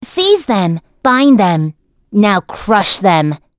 Gremory-Vox_Skill3.wav